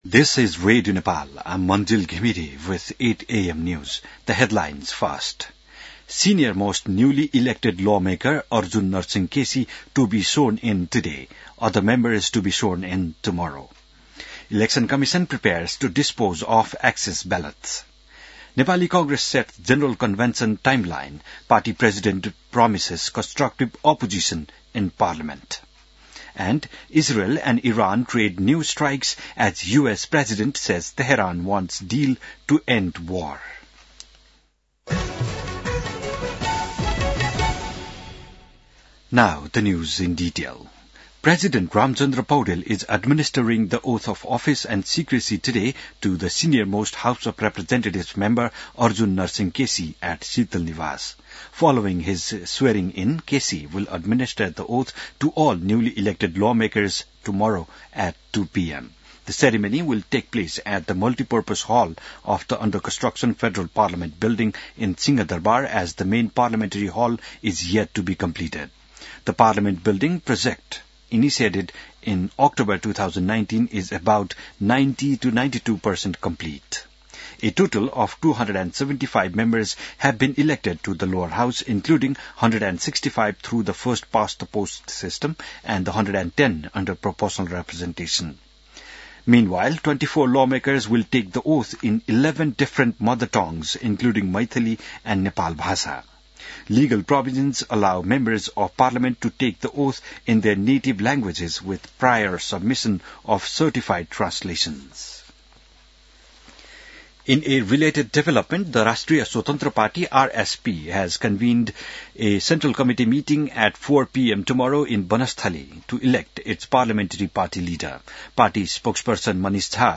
बिहान ८ बजेको अङ्ग्रेजी समाचार : ११ चैत , २०८२